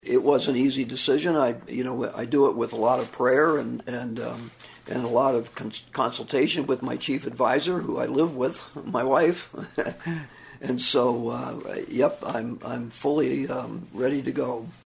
THE DISTRICT TWO SIOUX CITY REPUBLICAN SAYS HE STILL HAS WORK TO ACCOMPLISH IN SERVING THE PEOPLE OF SIOUXLAND: